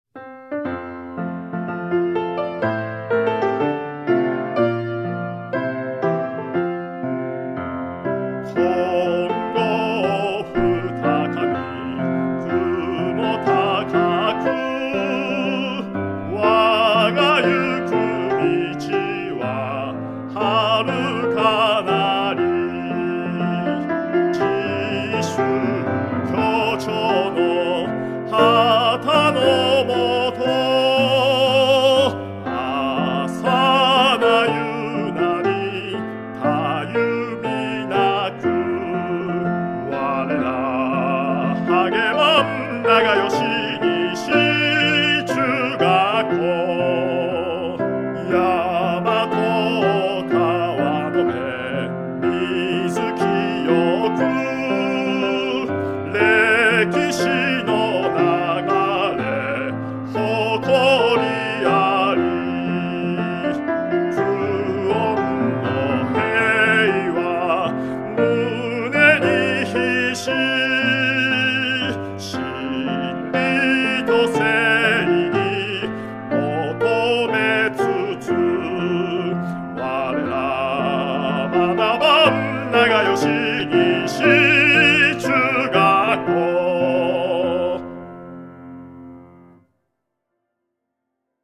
歌唱付き